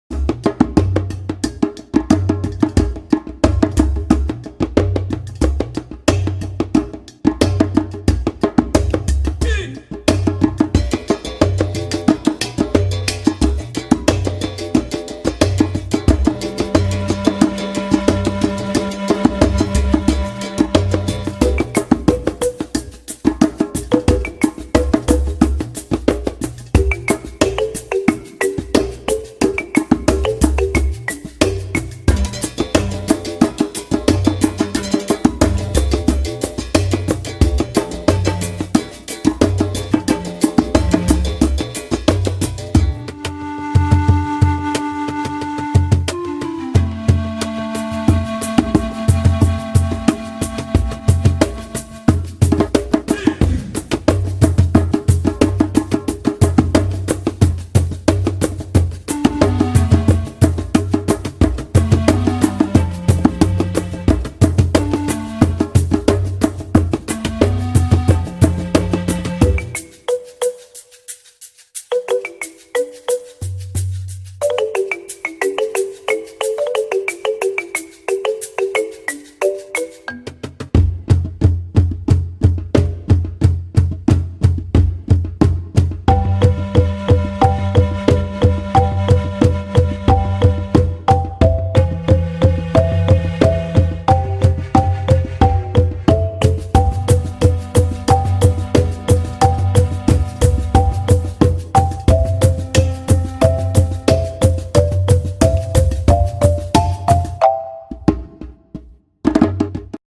MKAtF7QfwWI_Música-para-La-Selva-y-Naturaleza.mp3